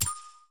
big_button.mp3